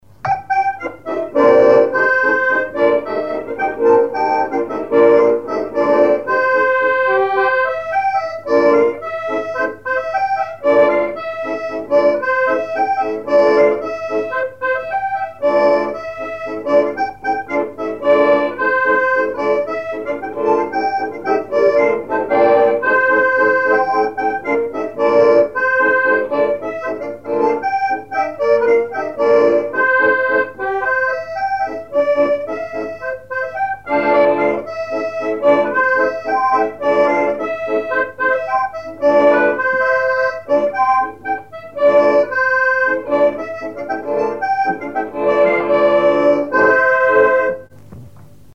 Fonction d'après l'analyste gestuel : à marcher
instrumentaux à l'accordéon diatonique
Pièce musicale inédite